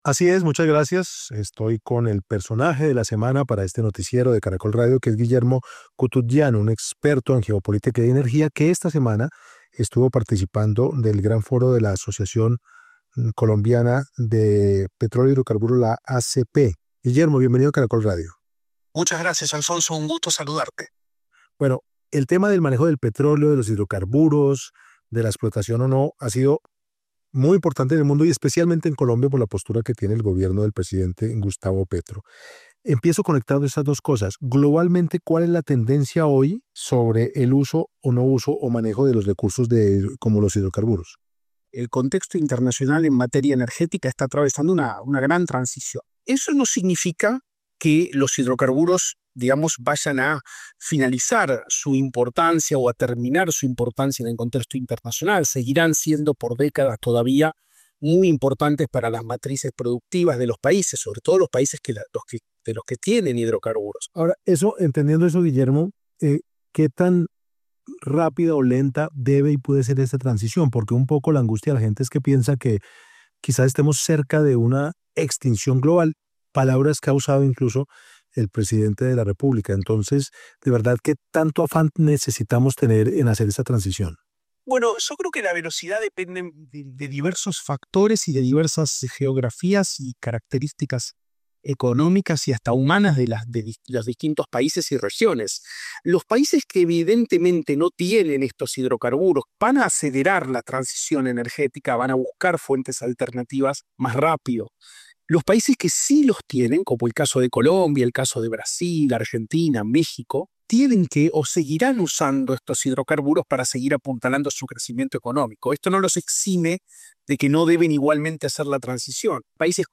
experto en geopolítica y energía